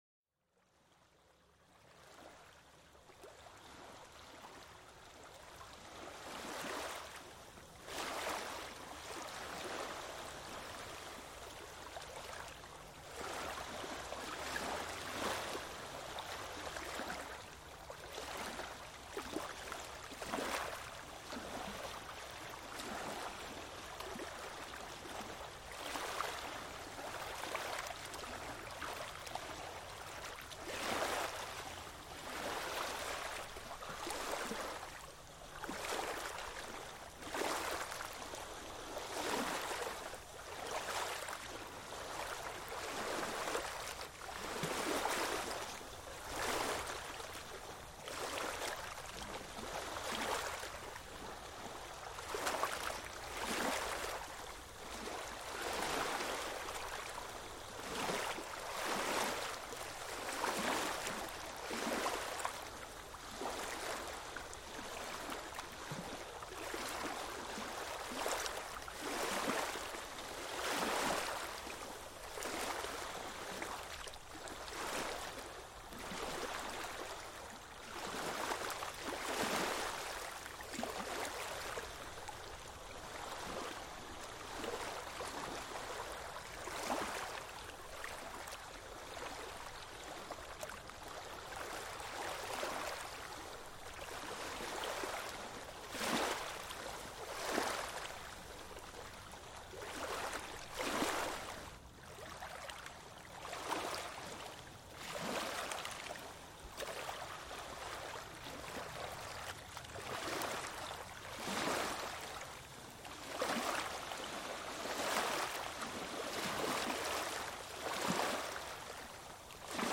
Pequeñas olas del mar: Calma y Armonía